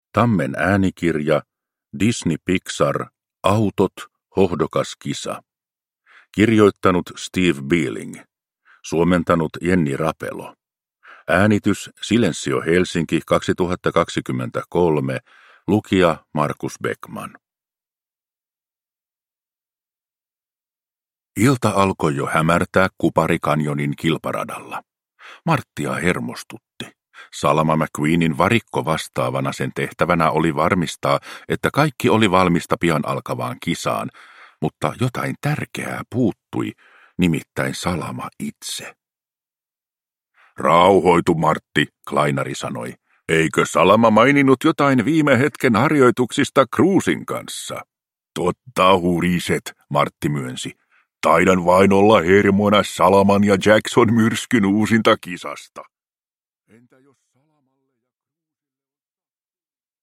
Disney Pixar. Autot. Hohdokas kisa – Ljudbok